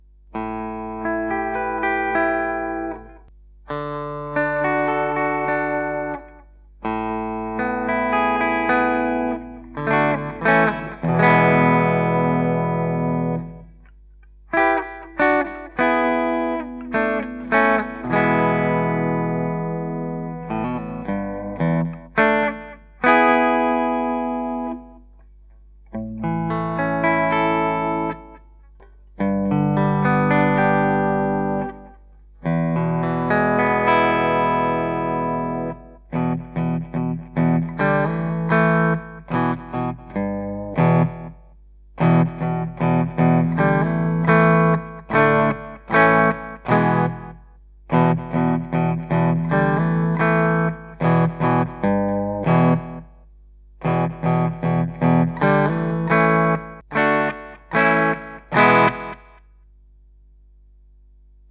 Archangel alnico 3 Soapbar P90, smooth clear tone
A traditional vintage winding together with alnico 3 magnets provides a softer attack, low string pull and great sustain combined with a balance between clarity and warmth that avoids both harshness and excess mids.